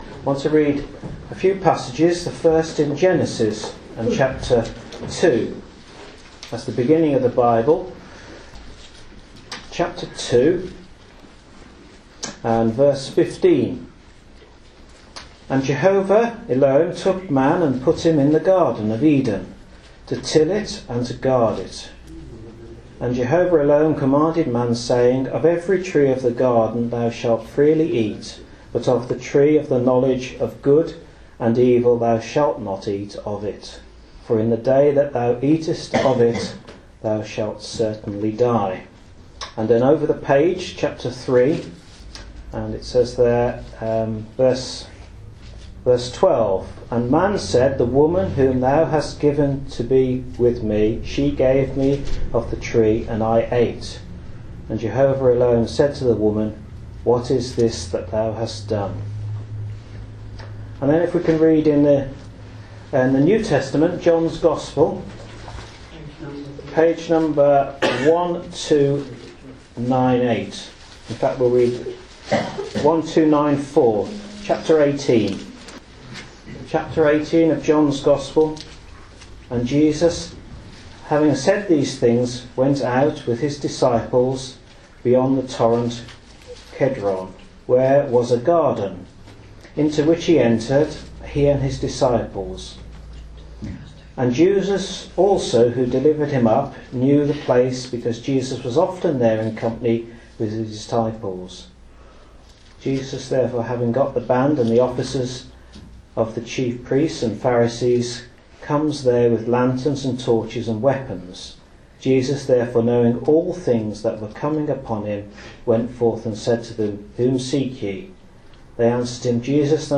In the following Gospel preaching, you will hear of the fall of man. When sin entered into the world it resulted in separation from a holy God.